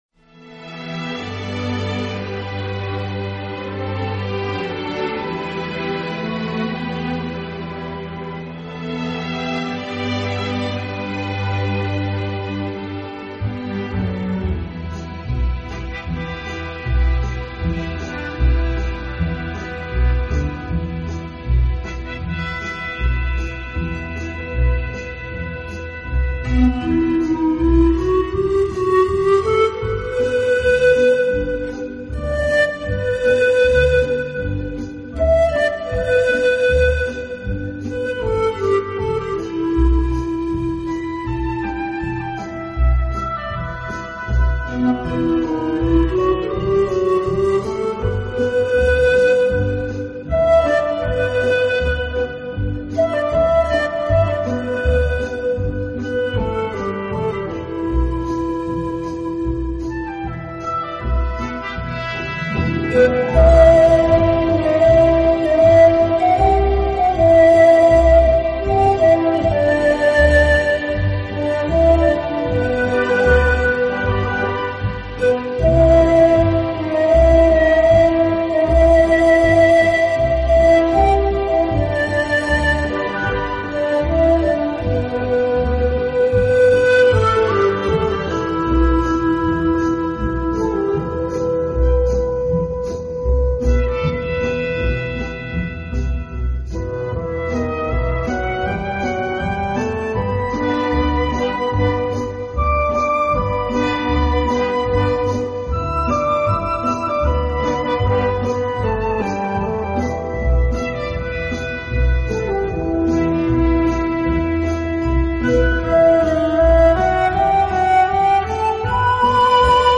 【顶级轻音乐】
而一流的錄音也使之成為試音的經典極品...